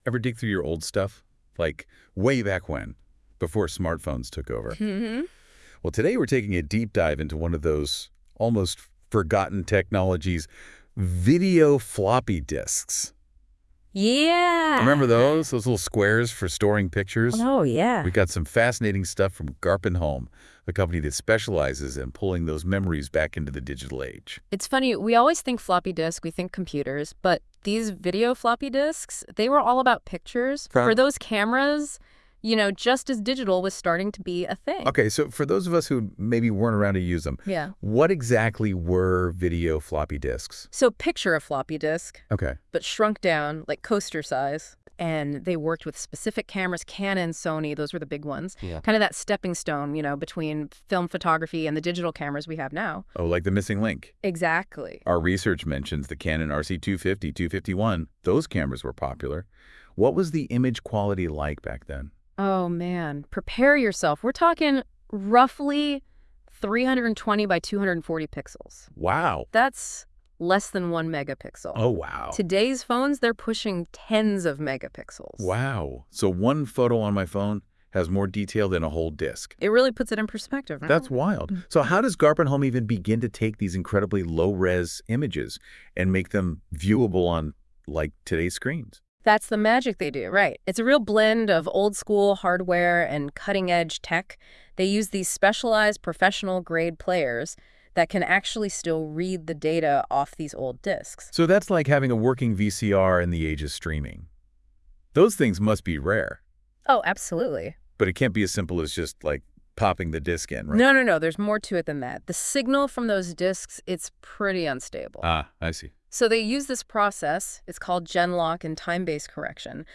Listen to a summary podcast by AI